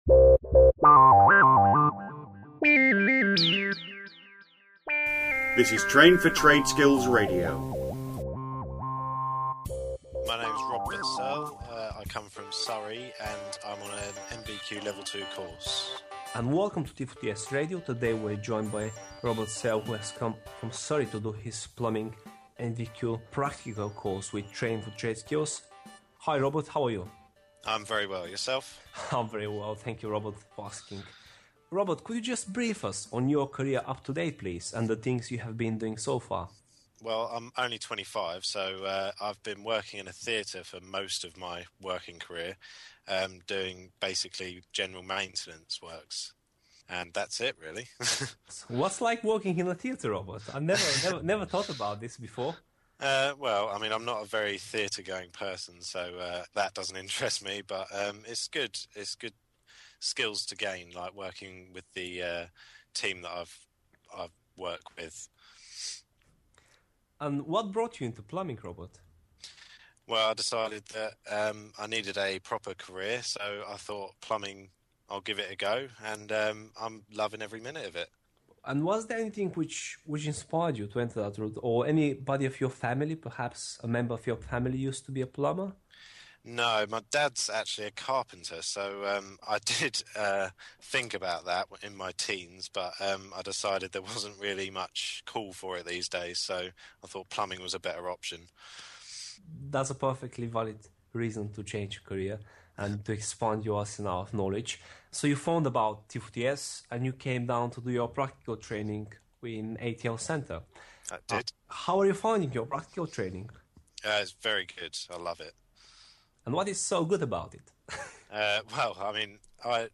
Train4TradeSkills Student Radio brings students talking about their experiences whilst studying and attending practical training. Many offer tips on getting work experience or jobs - some explain how to start a business and other explaining how including solar training can benefit your career.